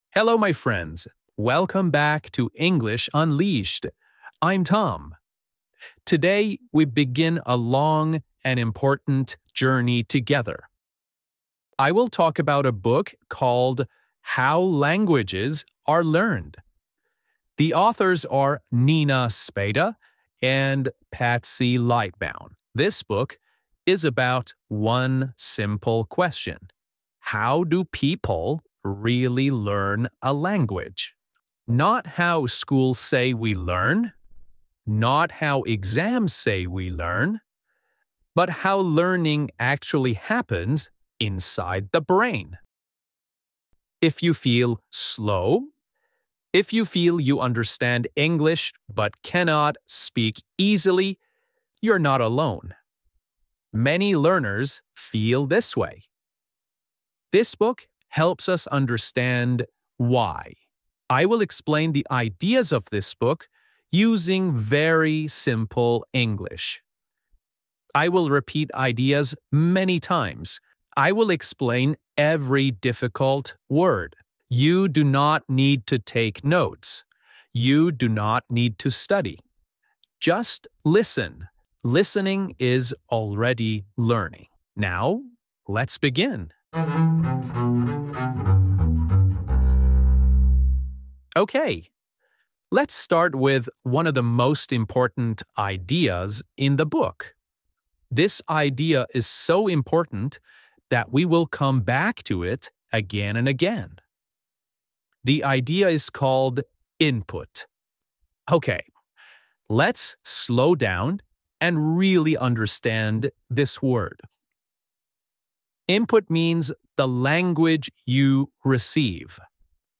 How Languages Are Really Learned | English Podcast to Improve Speaking | Slow English for Beginners
You will learn why listening comes before speaking, why making mistakes is normal, and why progress often feels invisible. Everything is explained in slow, clear English, with repetition and real-life examples, so you can understand naturally without stress.